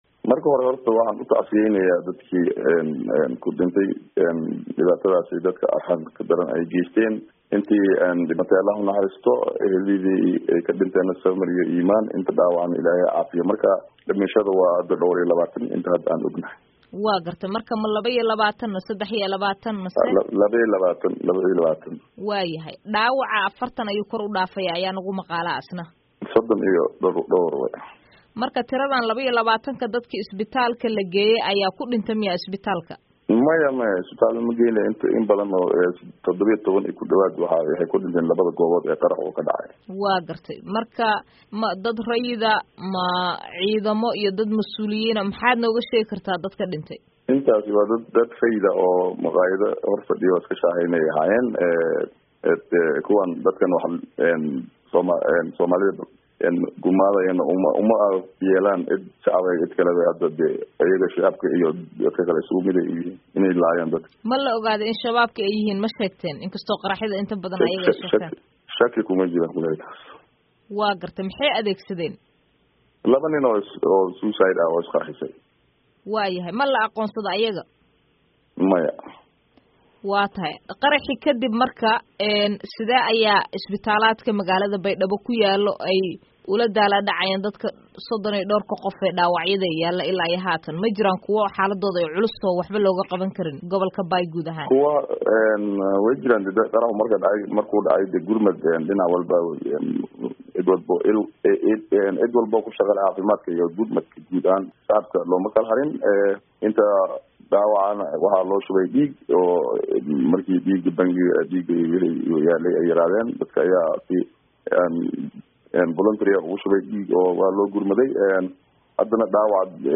Wareysi: Dhimashada Qaraxyadii Baydhabo oo kordhay